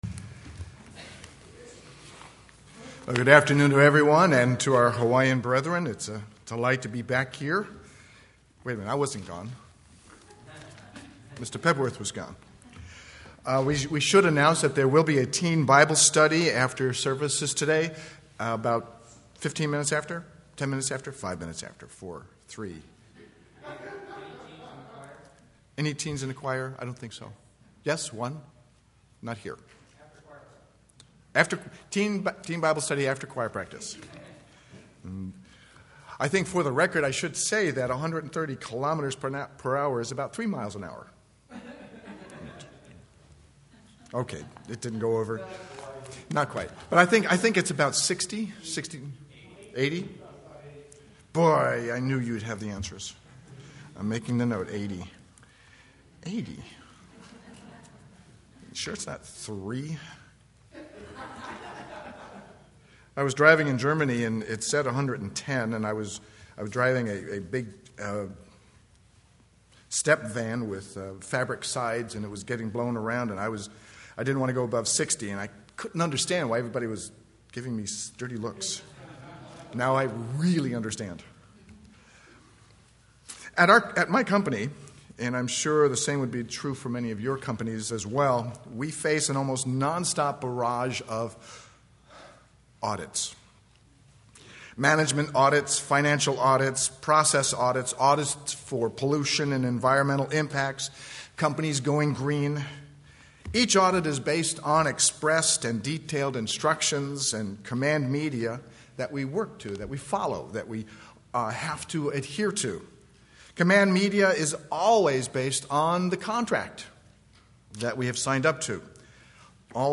This sermon describes how the congregation should prepare, realizing that every member of the body of Christ has his or her part to play. This message provides ways in which each member of the congregation can prepare for a spiritual harvest at the seminar./p>